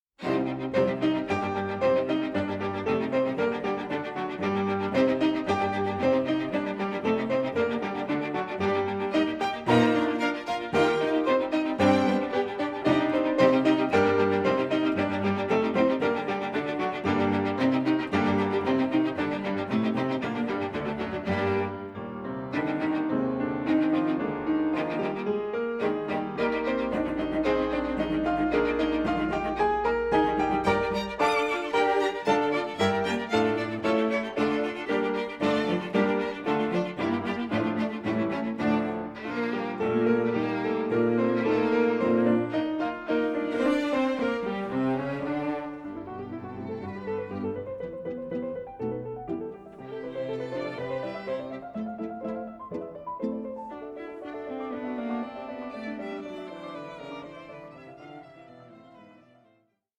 in high resolution sound quality